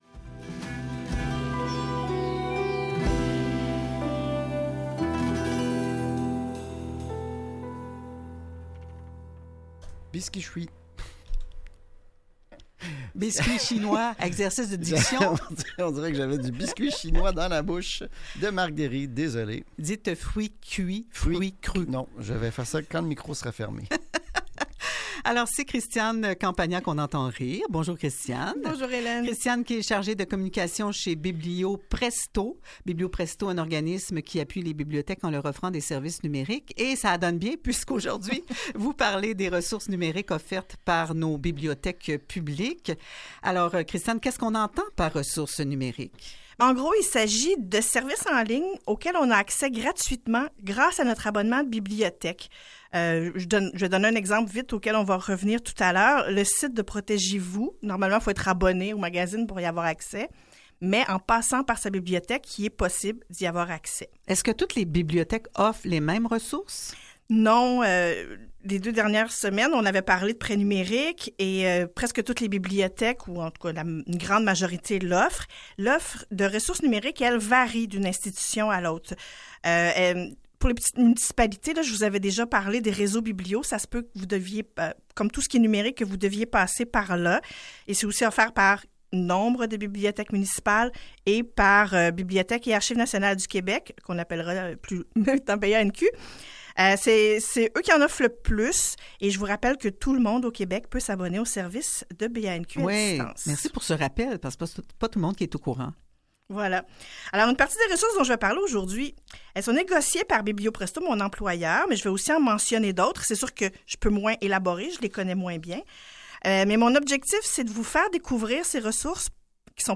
Entrevue sur les ressources numériques, 1re partie